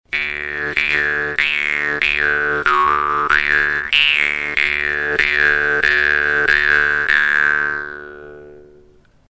Die Wimmer-Bades Pro erinnert von der Form her an die sehr einfachen Modelle aus der gleichen Werkstatt, ihr Klang ist allerdings klar und die Stimmung sauber.
Ihre relativ straffe Zunge erzeugt einen lauten, scharfen Sound, der auch auf der Bühne mithalten kann.